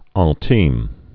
(ăɴ-tēm)